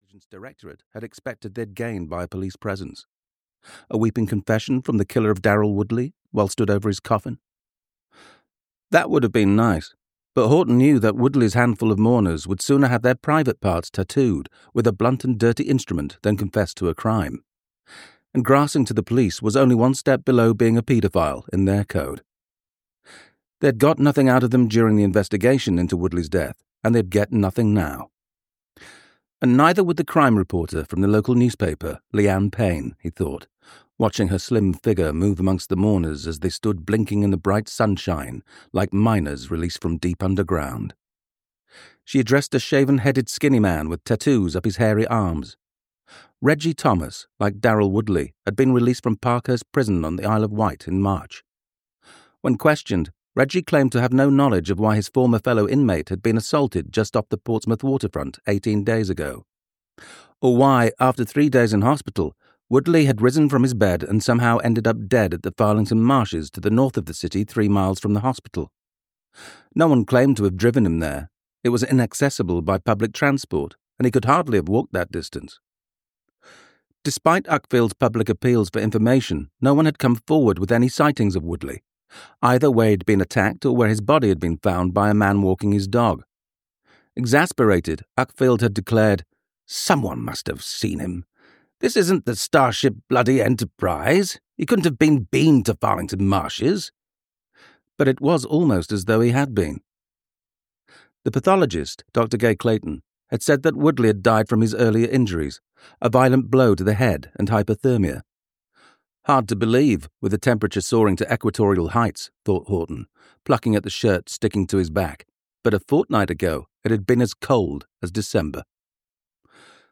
Audio knihaThe Farlington Marsh Murders (EN)
Ukázka z knihy
• InterpretAndrew Scarborough